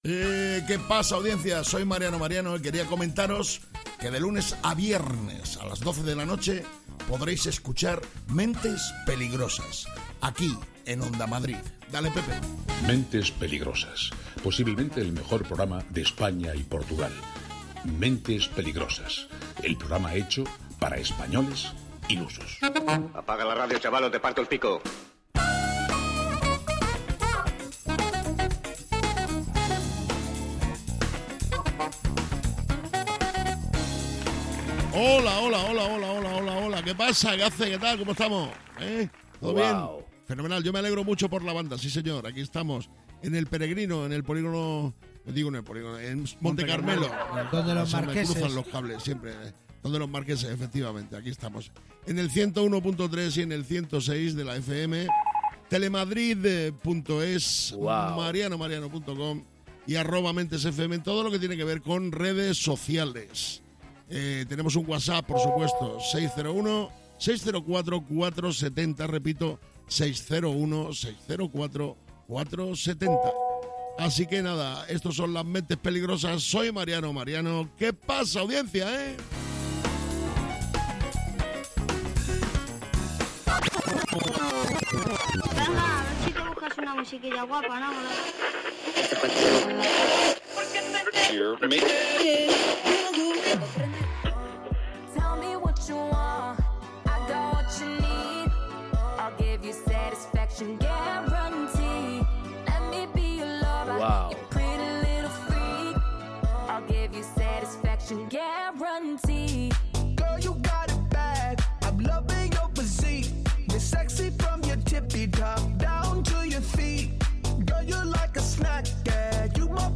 Mentes Peligrosas es un programa de radio, esto sí lo tenemos claro, lo que no está tan claro es qué pasará en cada una de sus entregas, no lo saben ni los que lo hacen, ni sus propios entornos. Mentes Peligrosas es humor, y quizás os preguntaréis, ¿y de qué tipo de humor es?